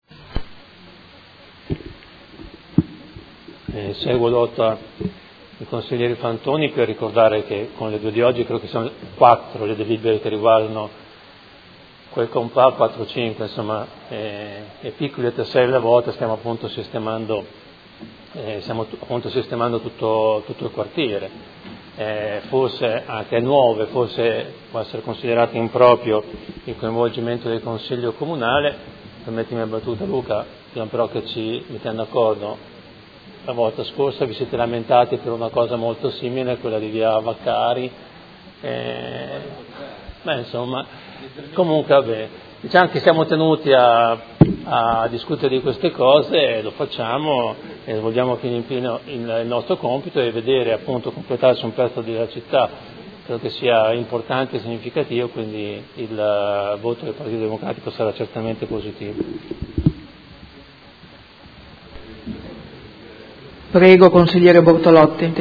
Seduta del 6 ottobre. Proposta di deliberazione: Permesso di costruire convenzionato per la realizzazione dell’intervento di trasformazione urbanistico-edilizia dell’area denominata “Abitcoop – Via Massolo”, zona elementare n. 1250 – area 06.
Dichiarazioni di voto